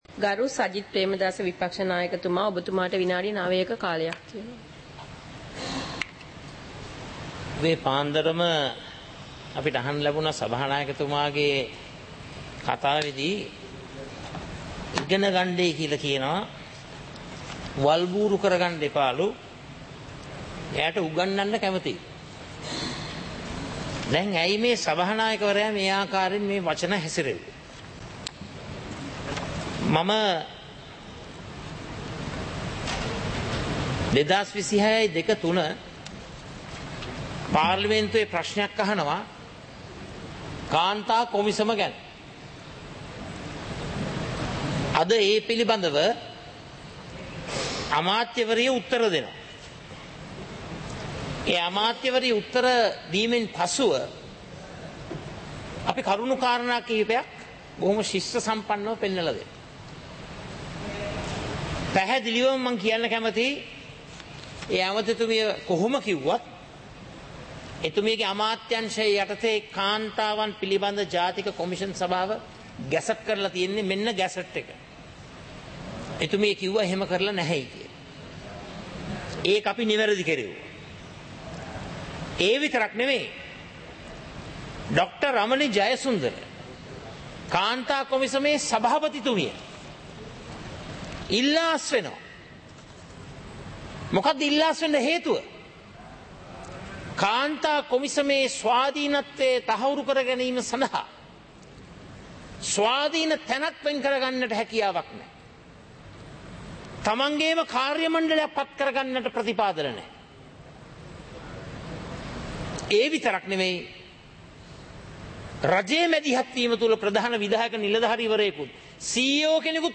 இலங்கை பாராளுமன்றம் - சபை நடவடிக்கைமுறை (2026-02-18)